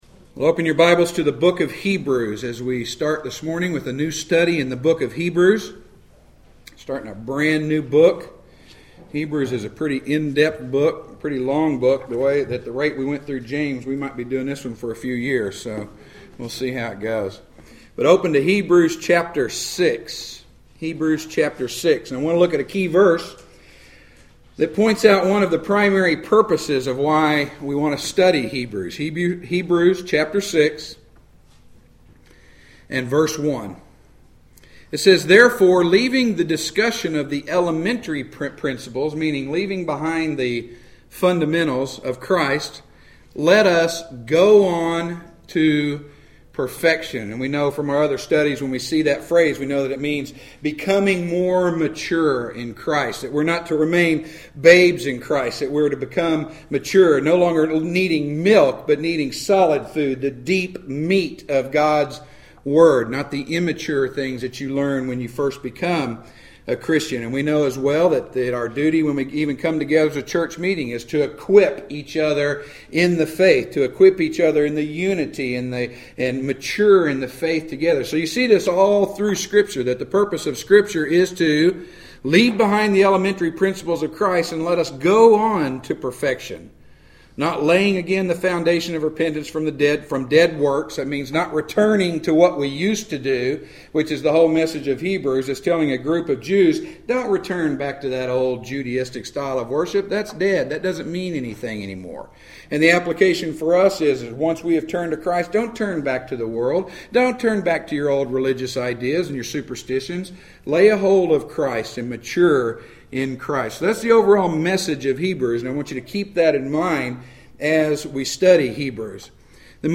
Preached at Straightway Bible Church on March 9, 2014.